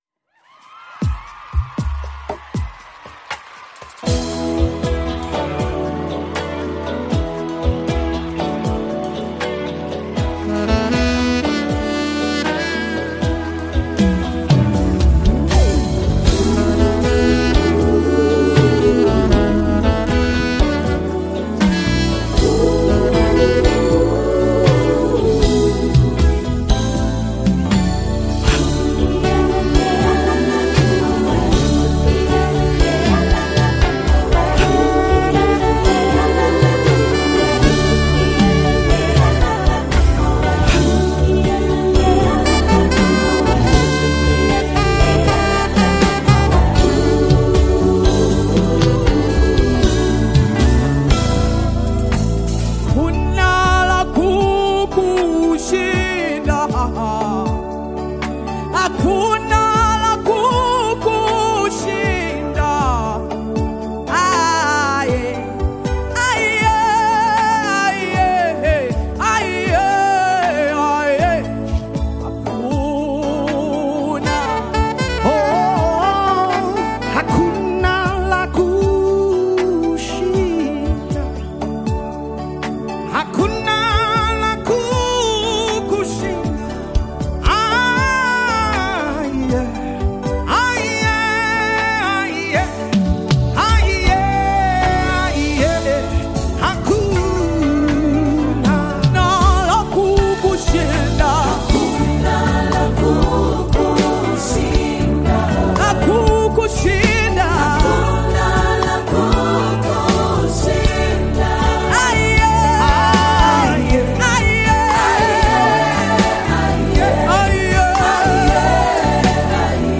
GOSPEL
powerful and spirit filled worship anthem
Known for her anointed voice and uplifting messages.